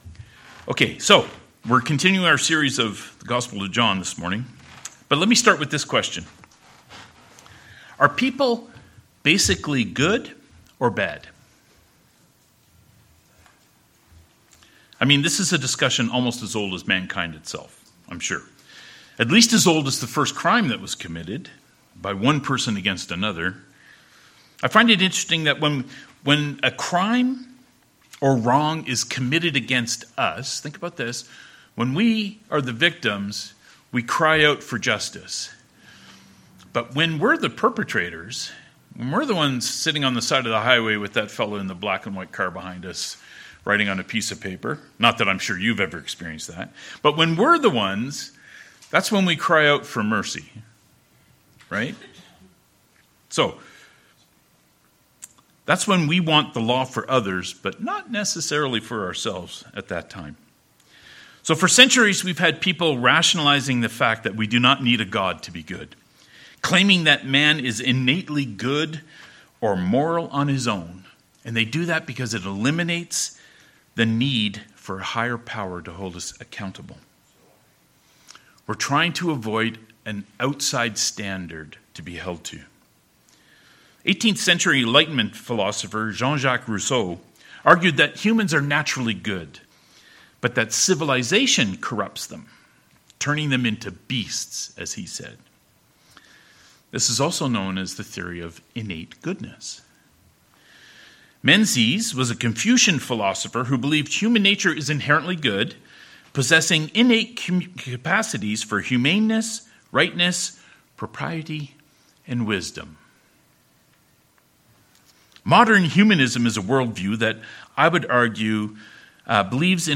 John "So That You May Believe" Passage: John 18: 12-14, 19-24 Service Type: Sermons « Was Jesus a Victim?